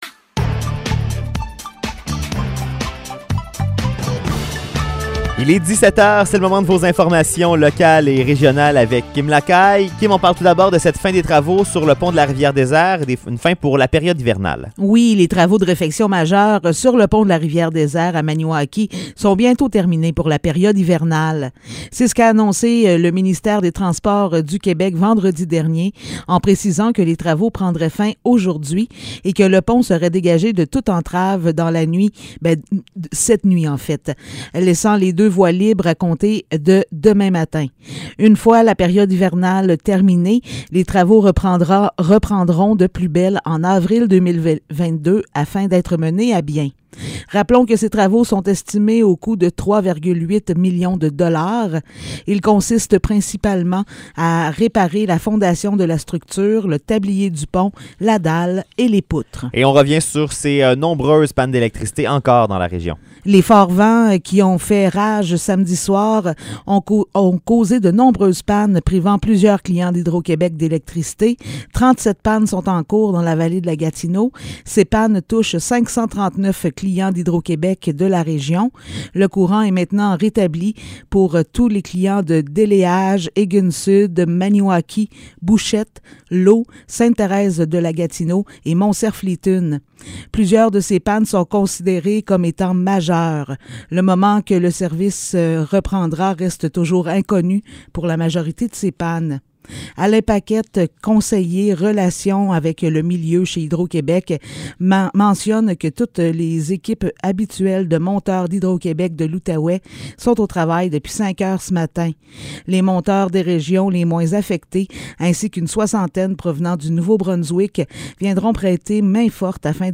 Nouvelles locales - 10 décembre 2021 - 17 h